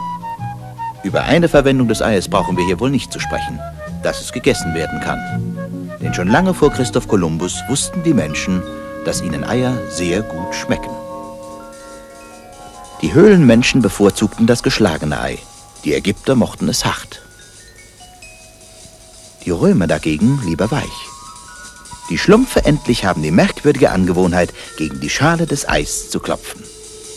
Erzähler: